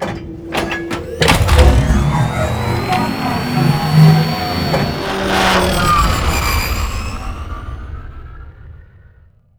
vehicleLaunch.wav